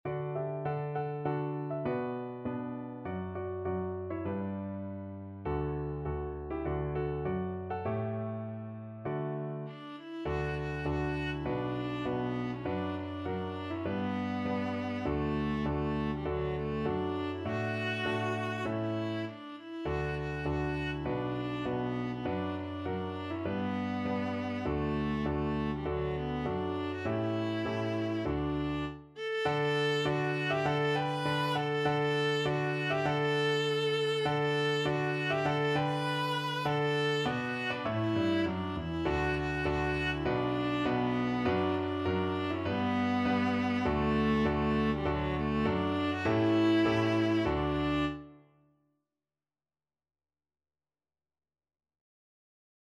Viola
D major (Sounding Pitch) (View more D major Music for Viola )
4/4 (View more 4/4 Music)
Moderato
Traditional (View more Traditional Viola Music)
Battle_Cry_of_Freedom_VLA.mp3